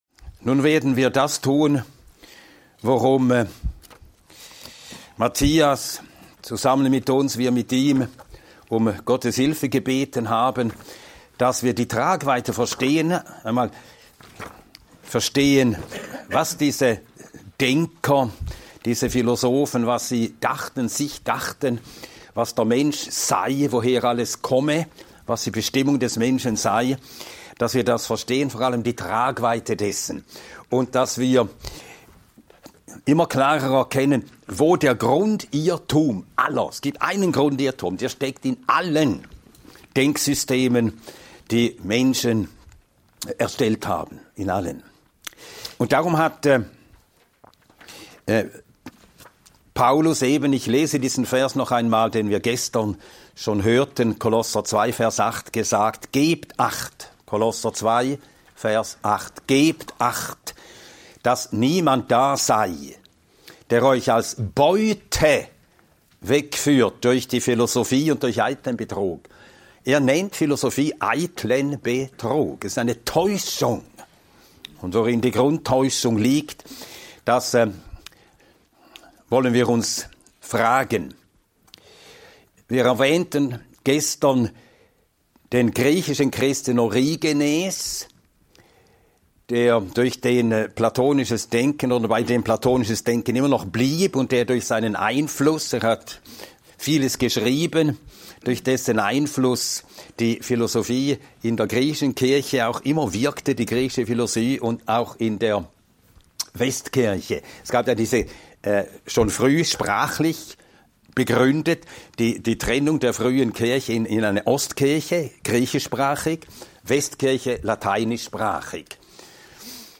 In diesem Vortrag werden die Grundzüge der griechischen Philosophie von den Vorsokratikern bis Platon nachgezeichnet und deren Einfluss auf Theologie und modernes Denken beleuchtet. Anhand von Römer 12,1-2 wird gezeigt, warum unser Denken erneuert werden muss und wie der biblische Maßstab uns Orientierung gibt – im Gegensatz zu den wechselnden Ideen der Philosophen.